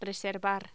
Sonidos: Voz humana